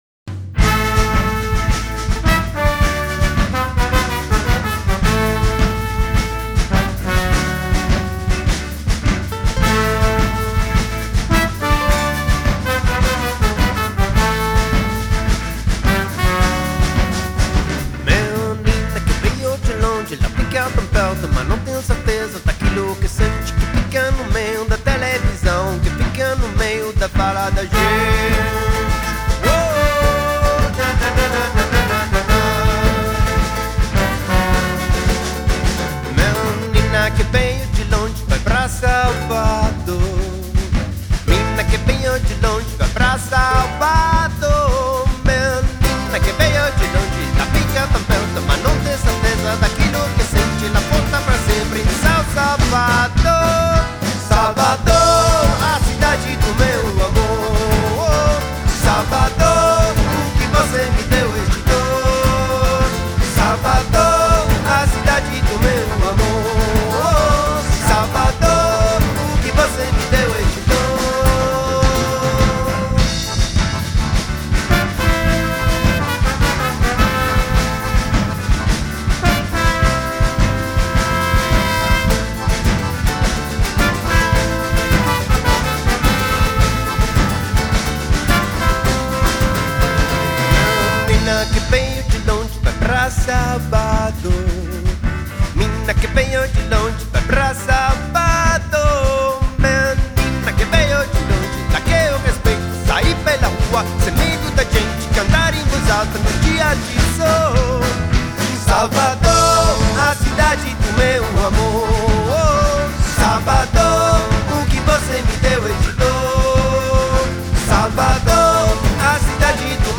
Il ritmo unico dello spaghetti samba da Bologna!